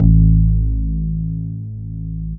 bseTTE48004moog-A.wav